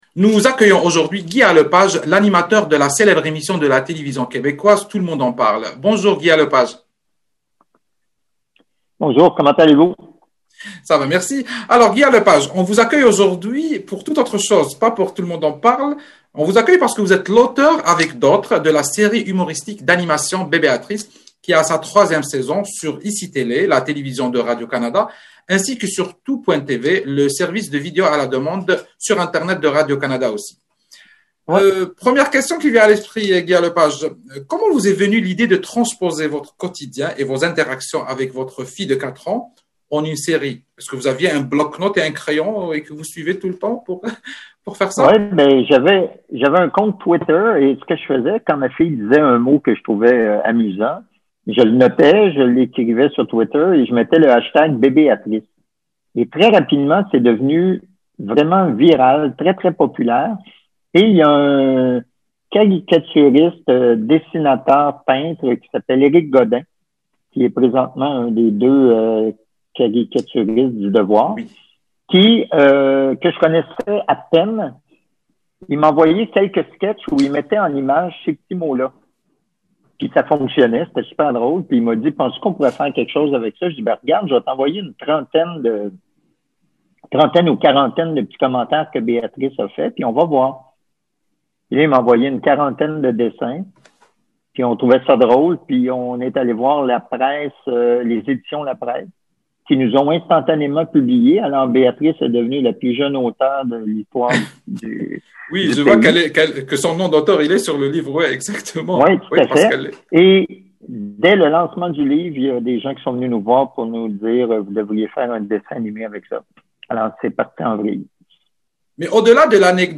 (Écouter l’entrevue avec Guy A. Lepage)